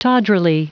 Prononciation du mot tawdrily en anglais (fichier audio)
Prononciation du mot : tawdrily